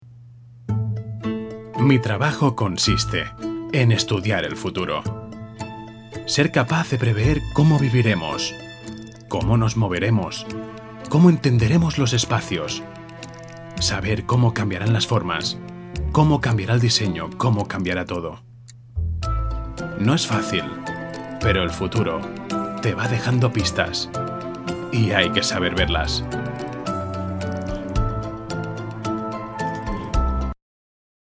Voz masculina adulto joven. Timbre grabe, dulce y profundo. Posee una musicalidad sensual y atractiva, cálida, próxima, segura y natural
Sprechprobe: Werbung (Muttersprache):